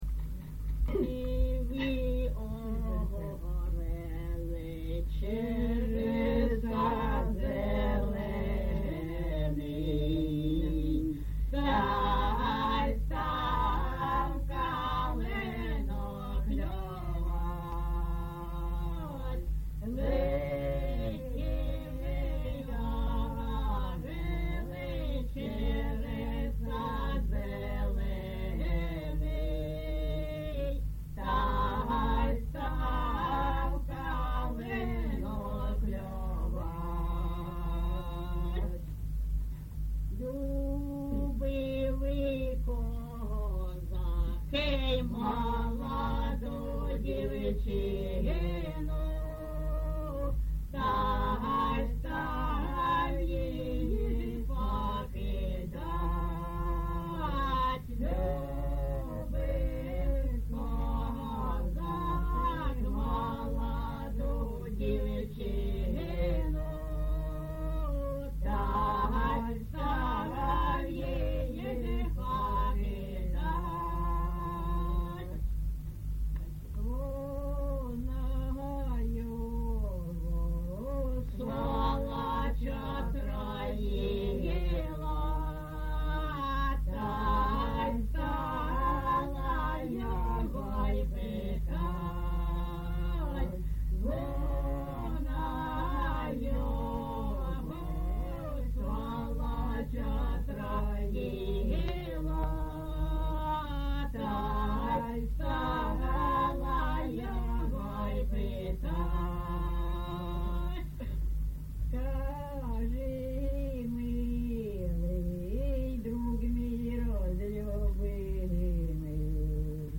ЖанрПісні з особистого та родинного життя, Балади
Місце записус. Званівка, Бахмутський район, Донецька обл., Україна, Слобожанщина